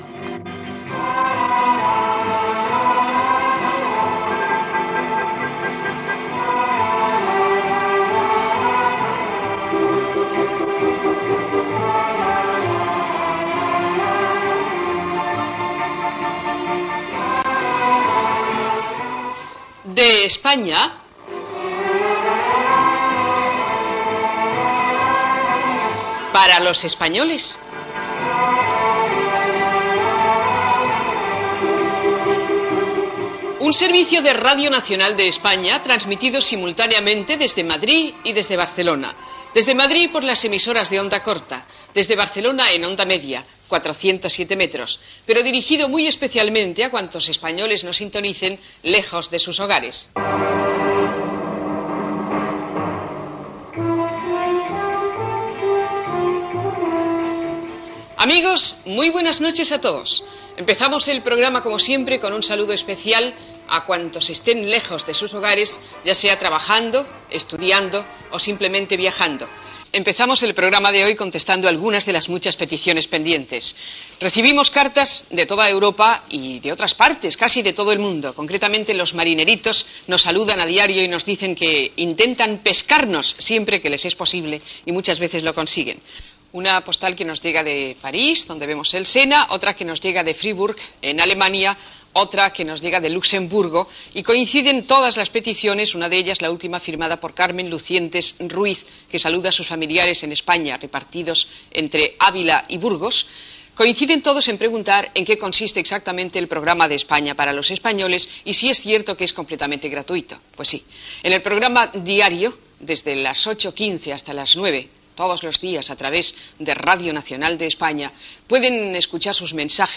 Sintonia del programa, presentació, postals rebudes i països de procedència i tema musical